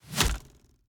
Bow Attacks Hits and Blocks
Bow Blocked 2.ogg